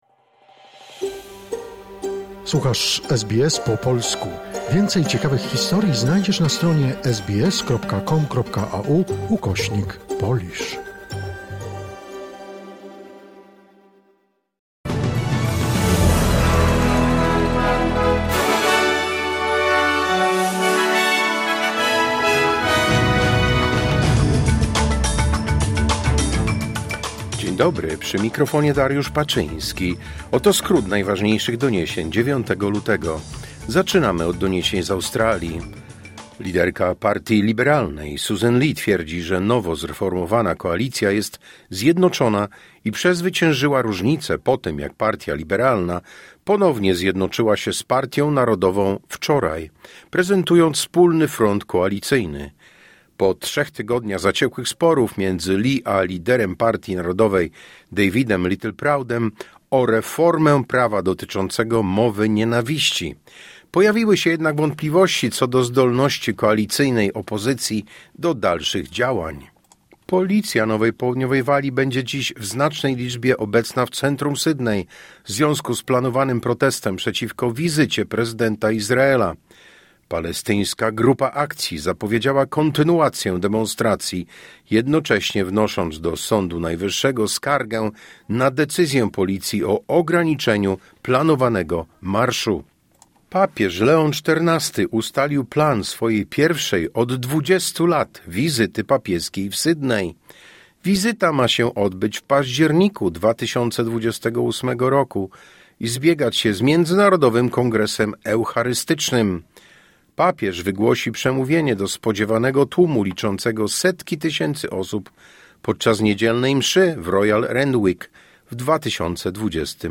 Wiadomości 9 lutego SBS News Flash